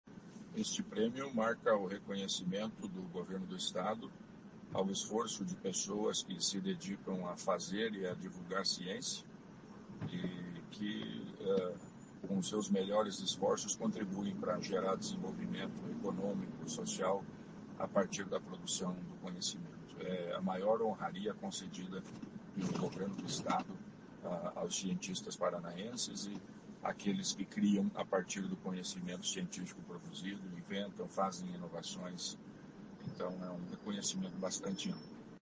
Sonora do secretário estadual da Ciência, Tecnologia e Ensino Superior, Aldo Nelson Bona, sobre premiação de iniciativas